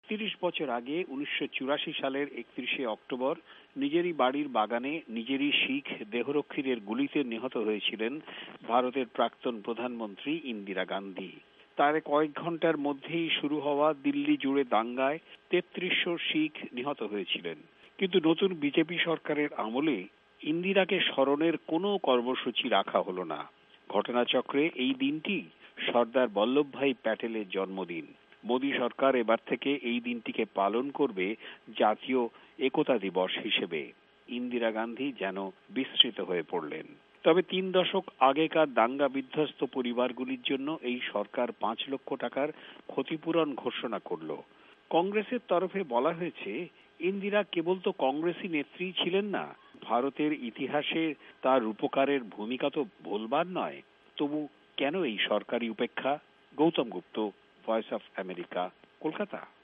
ভয়েস অব আমেরিকার কলকাতা সংবাদদাতাদের রিপোর্ট